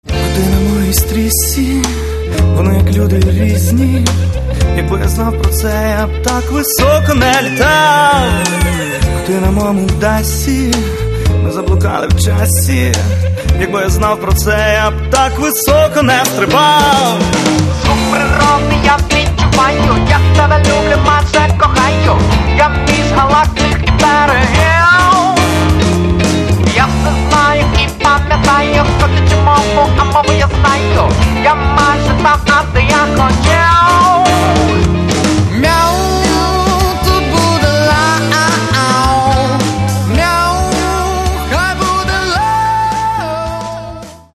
Каталог -> Рок та альтернатива -> Поп рок